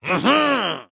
Minecraft Version Minecraft Version 25w18a Latest Release | Latest Snapshot 25w18a / assets / minecraft / sounds / mob / vindication_illager / celebrate1.ogg Compare With Compare With Latest Release | Latest Snapshot
celebrate1.ogg